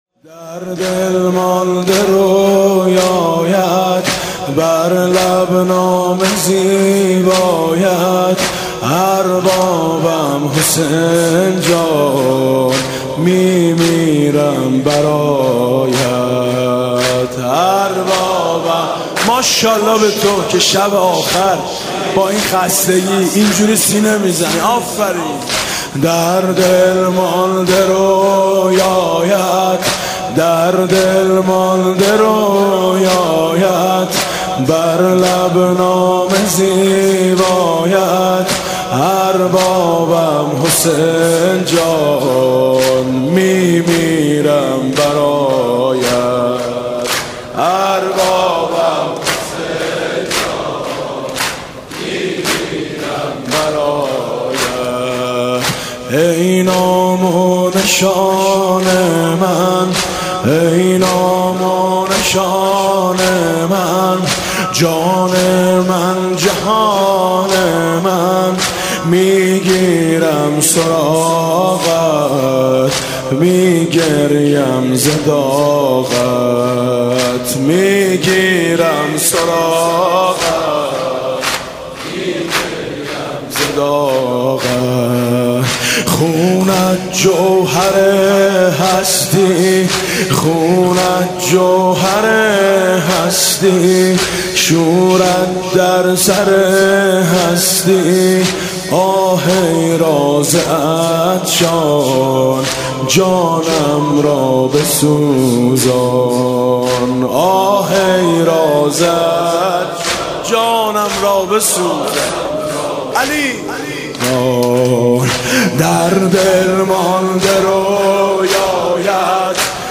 «محرم 1396» (شب یازدهم) زمینه: در دل مانده رویایت، بر لب نام زیبایت